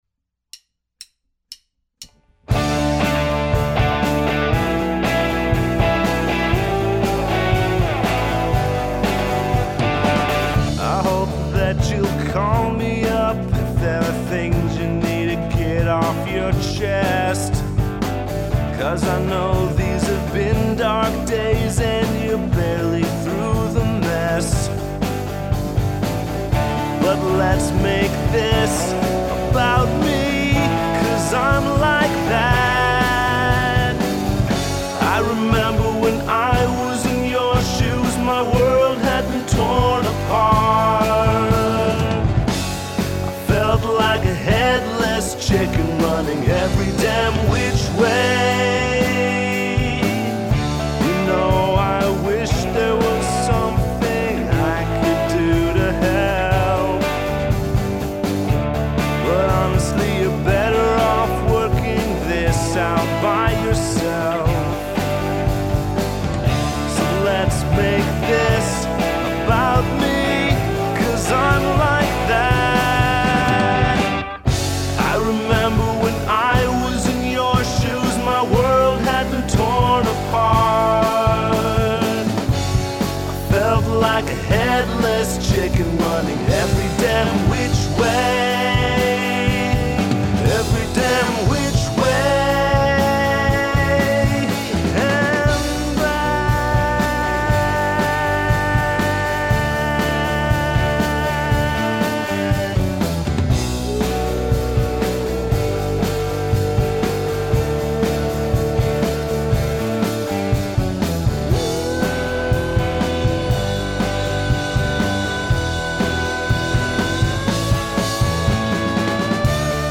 not the kind of song i typically write. i don’t usually… well… pop rock.